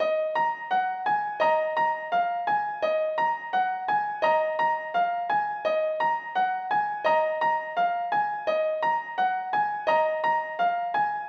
SAD LIL PEEP DISTORTED PIANO MELODY
描述：a sorrow piano pattern I made and added effects to in logic pro x.
标签： 170 bpm Rap Loops Piano Loops 1.90 MB wav Key : Unknown Logic Pro
声道立体声